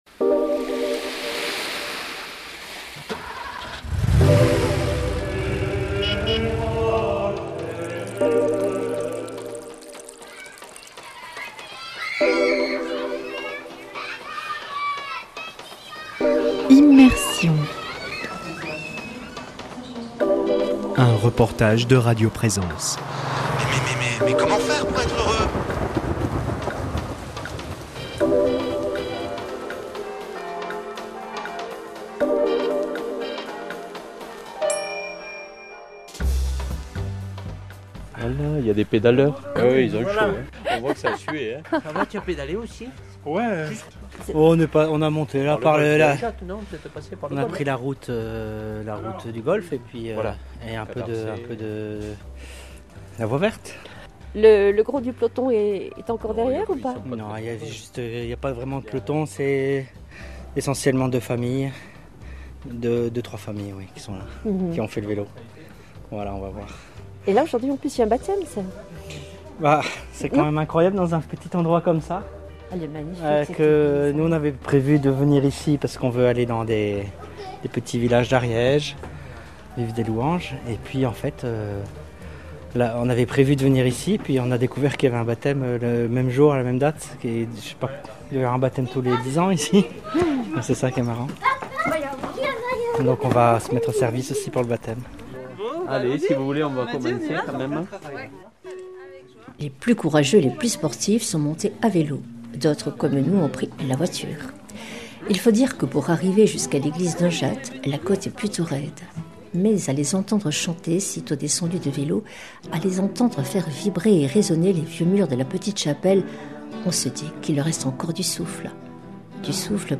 [ Rediffusion ] Les plus courageux, les plus sportifs sont montés à vélo, d’autres comme nous ont pris la voiture ! Il faut dire que pour arriver jusqu’à l’église d’Unjat la côte est plutôt raide mais à les entendre chanter, sitôt descendus de vélo, à les entendre faire vibrer et resonner les vieux murs de la petite chapelle, on se dit qu’il leur reste encore du souffle !